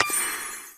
Spin_Button_Click.mp3